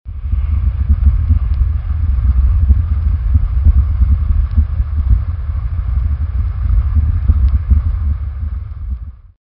Звуки пожара
Звук пожара сквозь бетонную стену